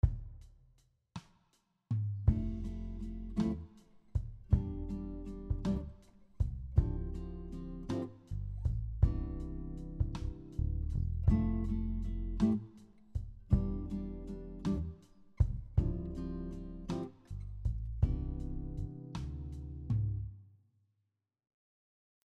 Here are some musical examples using shell chords including tabs and audio.
Shell chord progression example 1
The first chord progression is in the key of C Major.